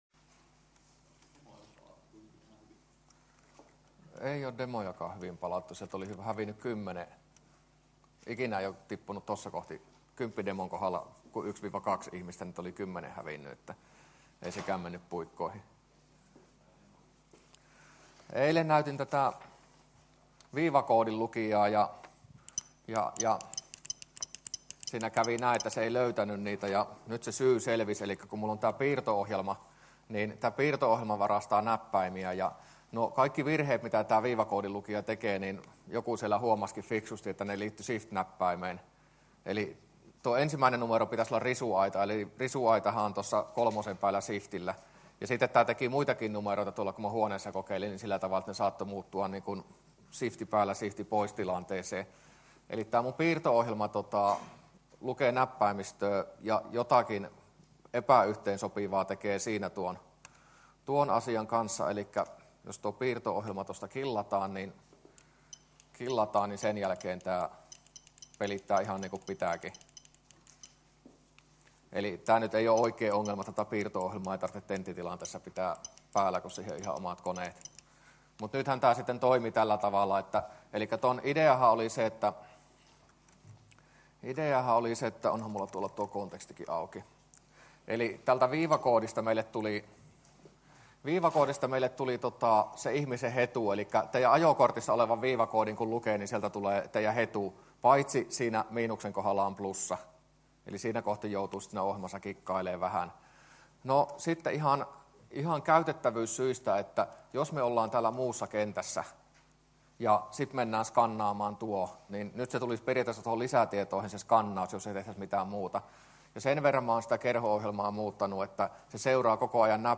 luento22a